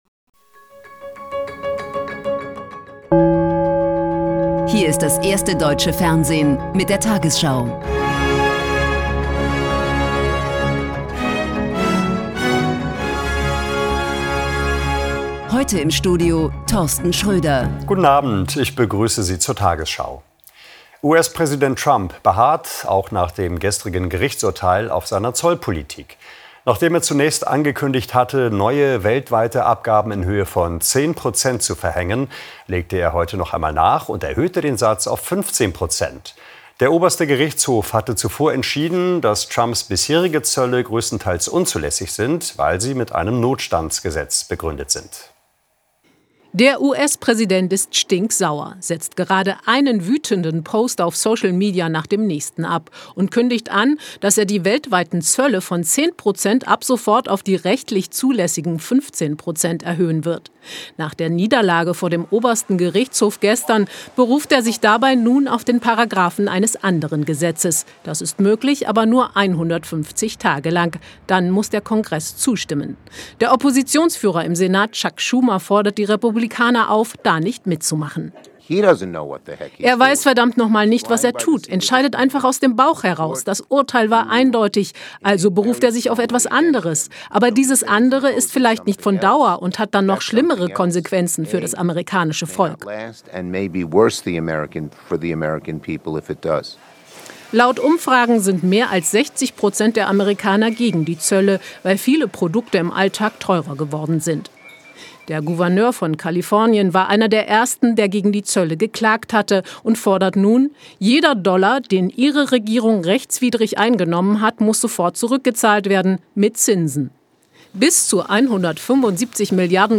tagesschau: Die 20 Uhr Nachrichten (Audio)